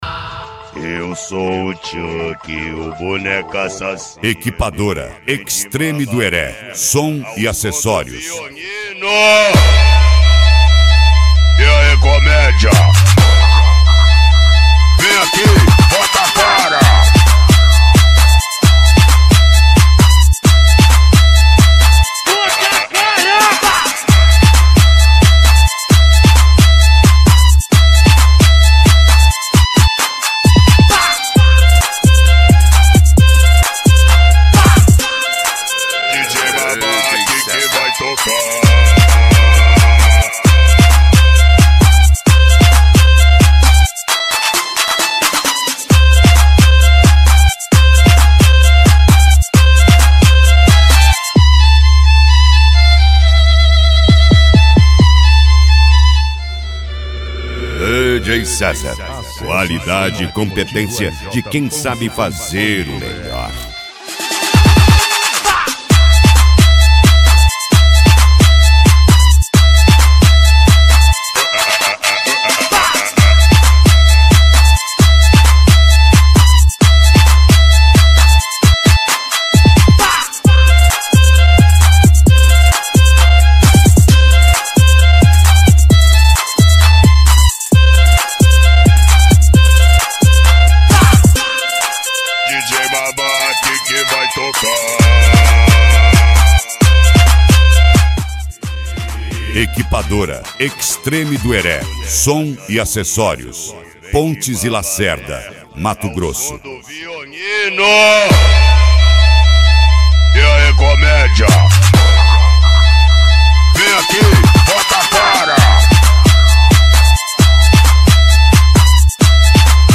Deep House
Funk
Mega Funk
SERTANEJO